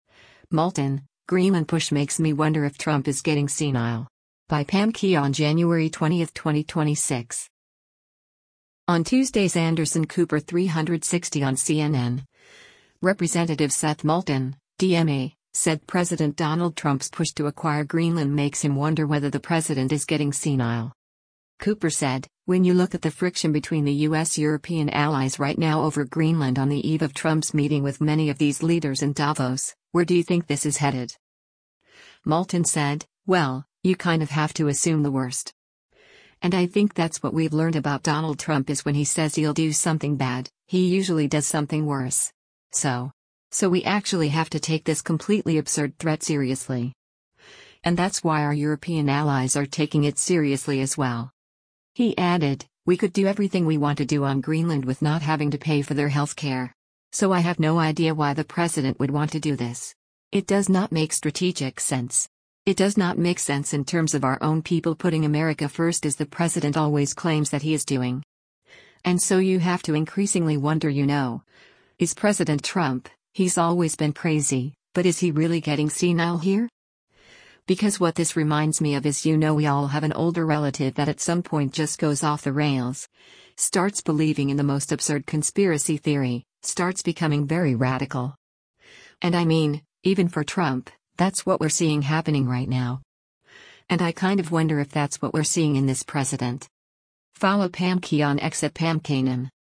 On Tuesday’s “Anderson Cooper 360” on CNN, Rep. Seth Moulton (D-MA) said President Donald Trump’s push to acquire Greenland makes him wonder whether the president is “getting senile.”